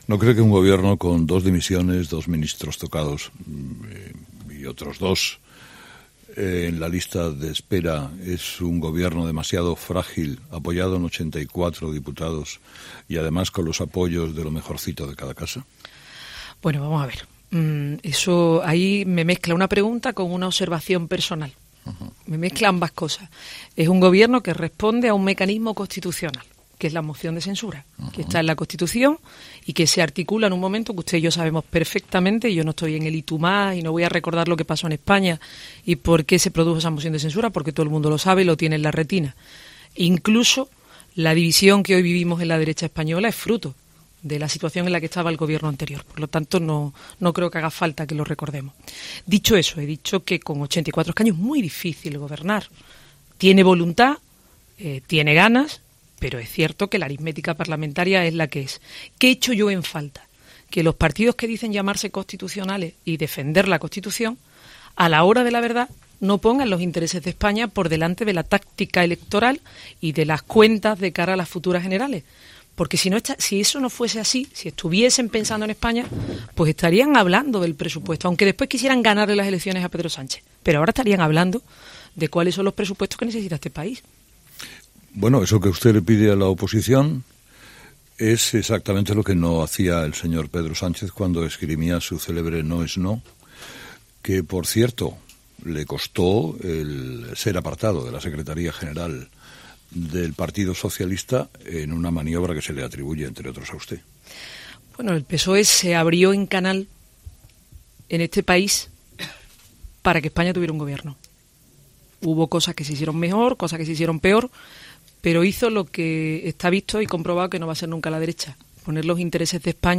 La presidenta andaluza ha pasado por los micrófonos de 'Herrera en COPE' tras anunciar el adelanto de las elecciones autonómicas para el 2 de diciembre
Entrevista completa a Susana Díaz en Herrera en COPE